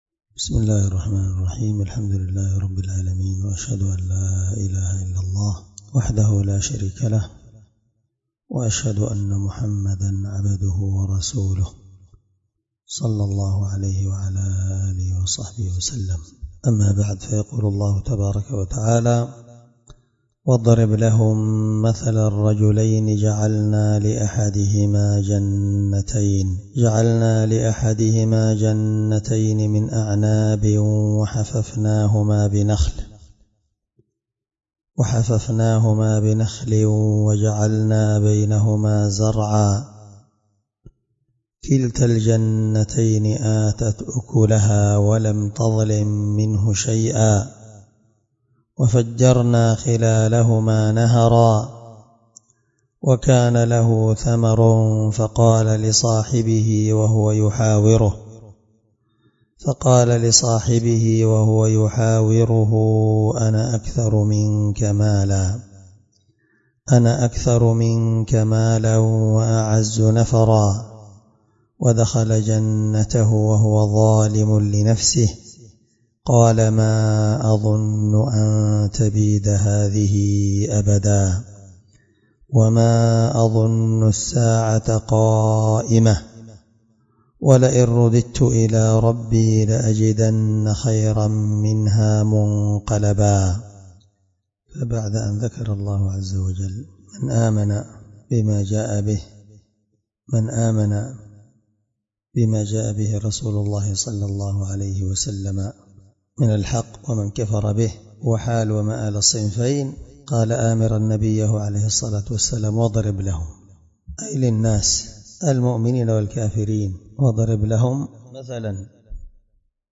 الدرس14 تفسير آية (32-36) من سورة الكهف
18سورة الكهف مع قراءة لتفسير السعدي